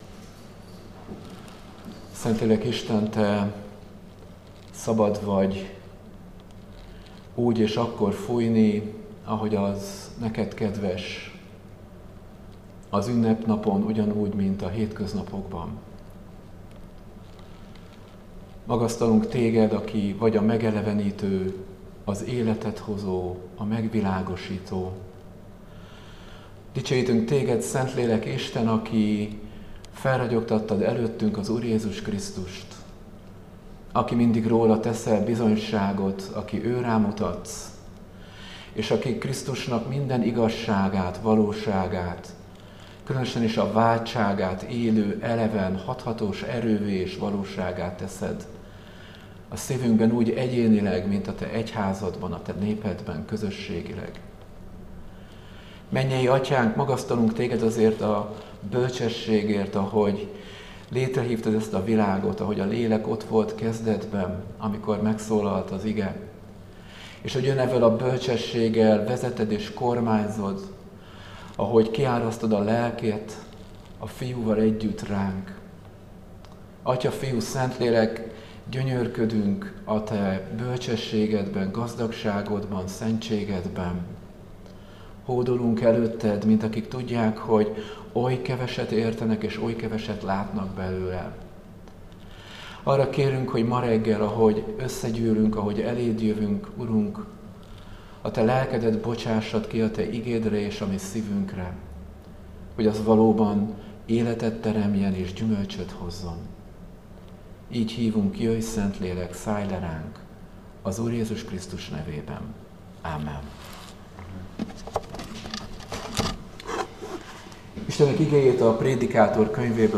Áhítat, 2025. június 10.